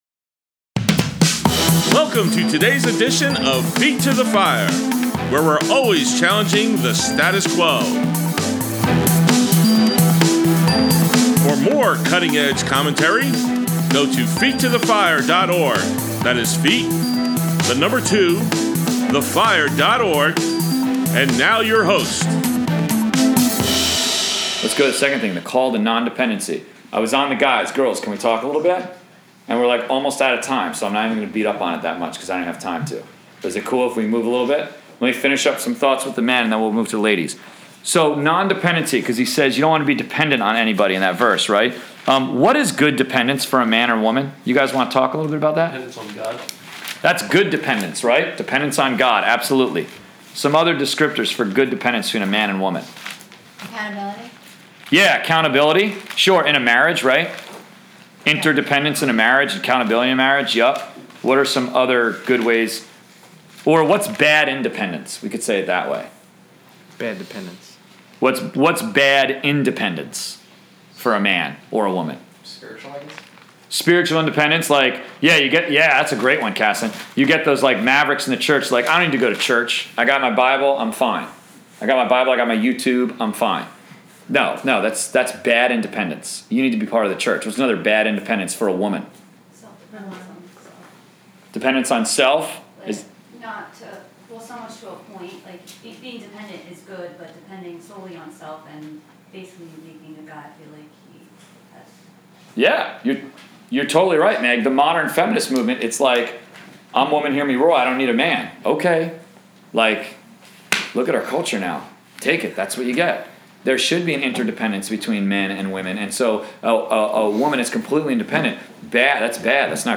College/Career Bible Study, April 1, 2017: Part 3 of 3